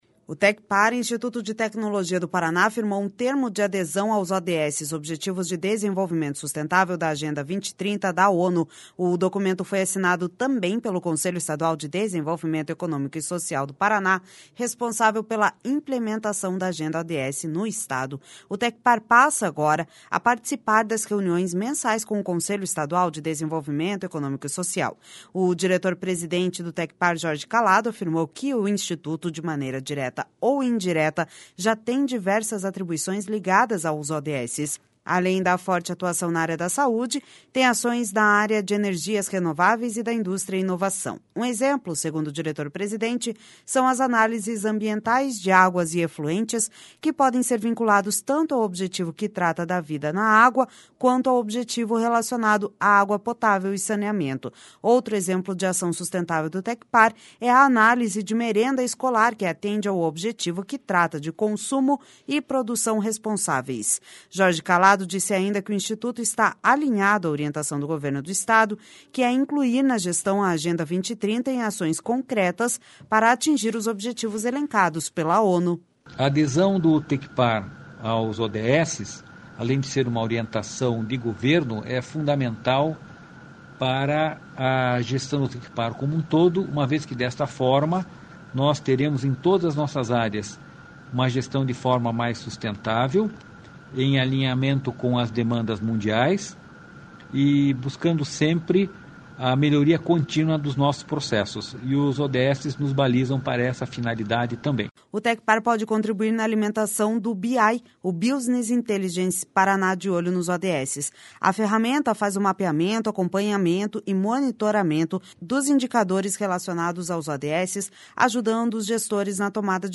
Jorge Callado disse, ainda, que o instituto está alinhado à orientação do Governo do Estado, que é incluir na gestão a Agenda 2030 em ações concretas para atingir os objetivos elencados pela ONU.// SONORA JORGE CALLADO//O Tecpar pode contribuir na alimentação do BI, o Business Intelligence, Paraná de Olho nos ODS.
A vice-presidente do Conselho Estadual de Desenvolvimento Econômico e Social, Keli Guimarães, disse que o Paraná é pioneiro tanto no Brasil, quanto fora dele, em relação à Agenda 2030.// SONORA KELI GUIMARÃES//Em 2015, líderes do mundo todo se comprometeram na ONU a implementar 17 Objetivos de Desenvolvimento Sustentável até 2030.